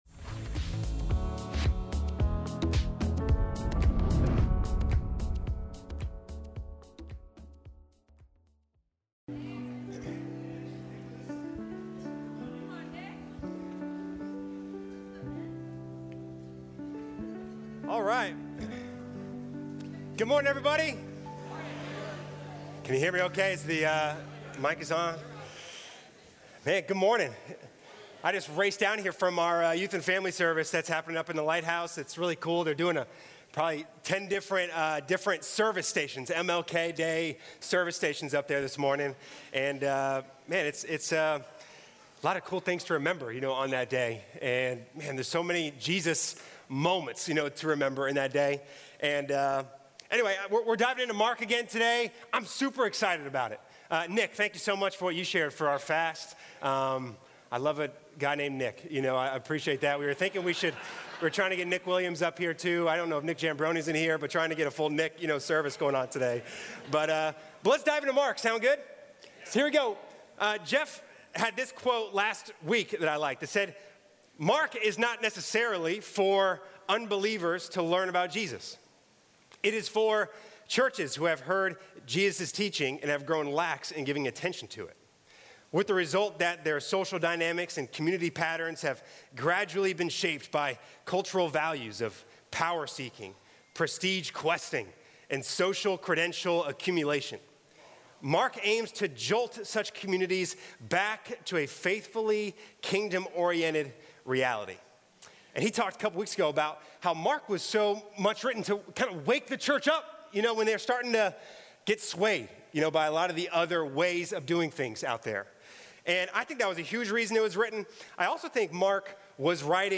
Sermons | North River Church of Christ